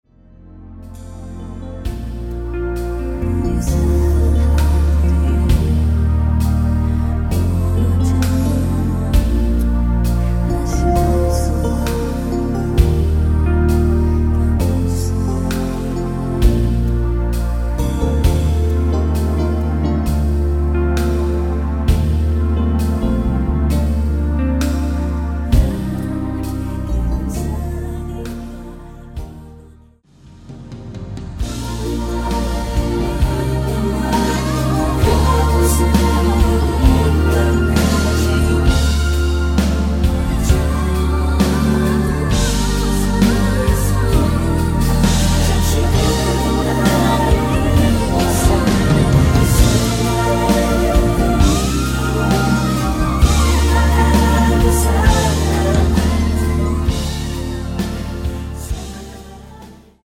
(-1) 내린 코러스 포함된 MR 입니다.(미리듣기 참조)
Bb
앞부분30초, 뒷부분30초씩 편집해서 올려 드리고 있습니다.
중간에 음이 끈어지고 다시 나오는 이유는